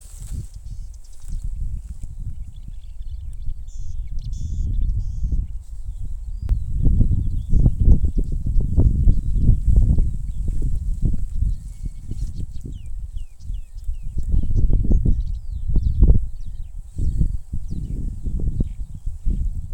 Purva ķauķis, Acrocephalus palustris
StatussDzirdēta balss, saucieni
PiezīmesDziedāja nepilnu minūti rapšu laukā. 100m attālumā no dīķa ar krūmājiem.